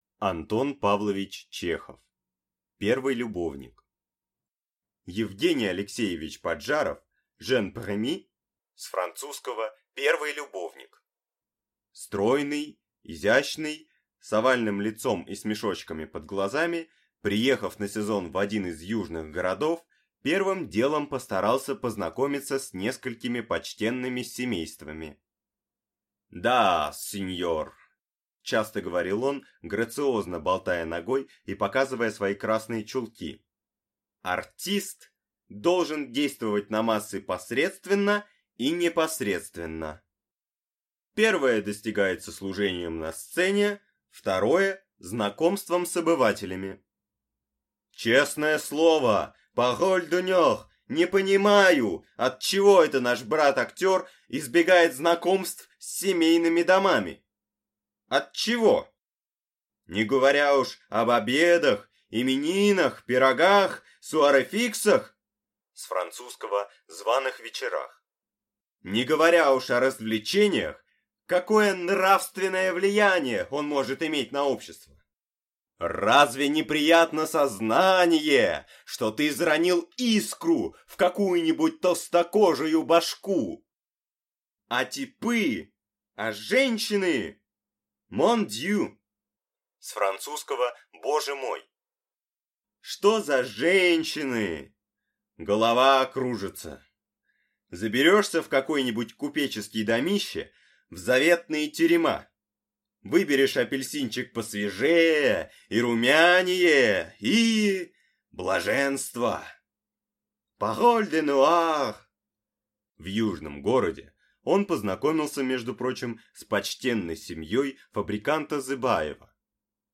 Аудиокнига Первый любовник | Библиотека аудиокниг